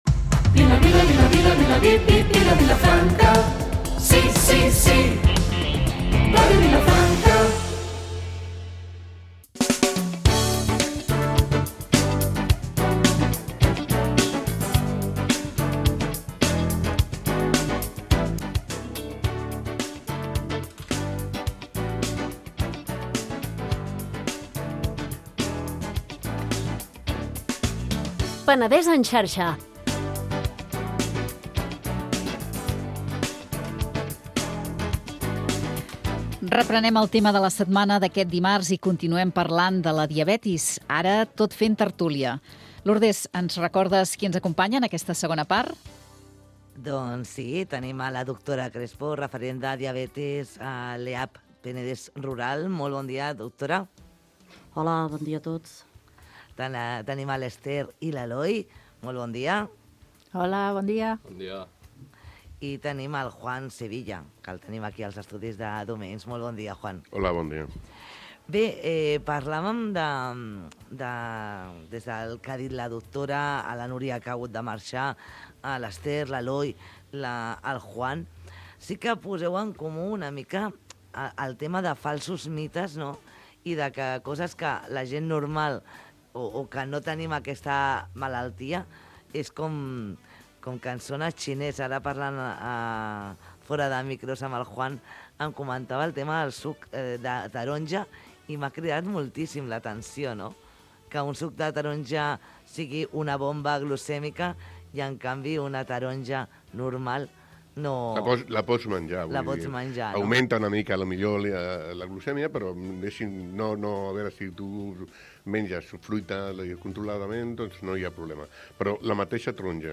Magazín matinal